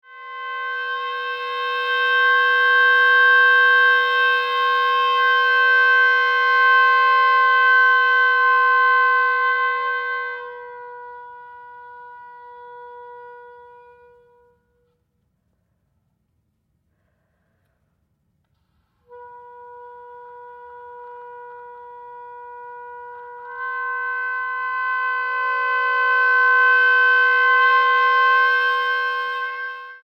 oboe